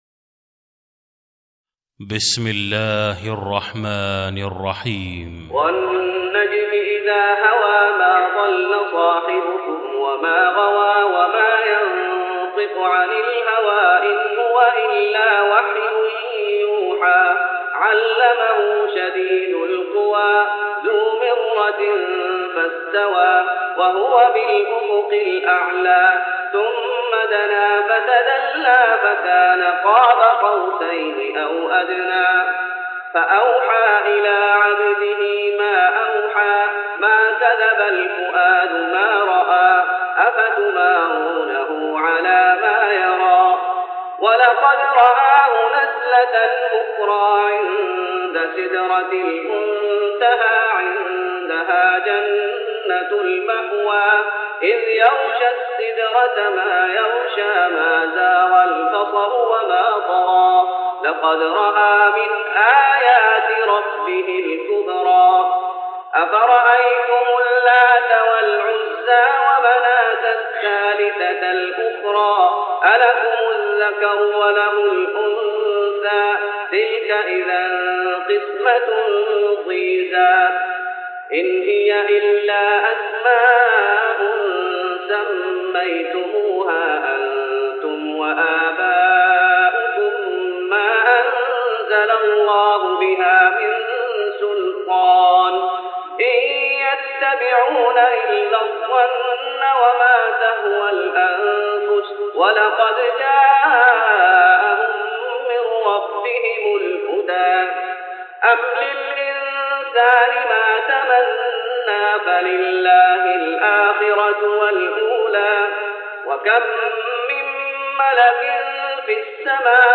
تراويح رمضان 1414هـ من سورة النجم Taraweeh Ramadan 1414H from Surah An-Najm > تراويح الشيخ محمد أيوب بالنبوي 1414 🕌 > التراويح - تلاوات الحرمين